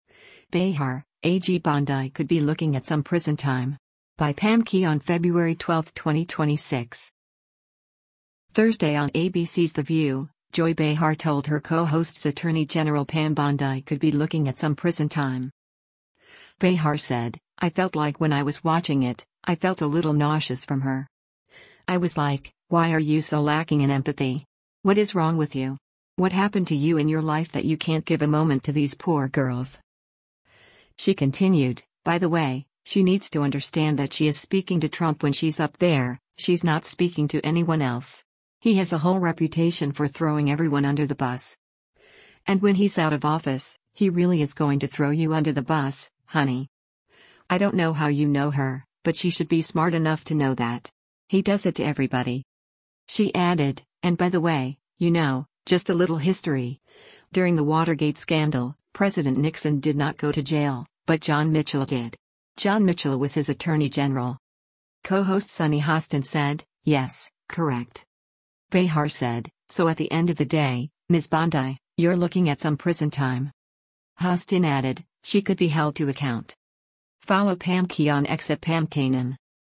Co-host Sunny Hostin said, “Yes, correct.”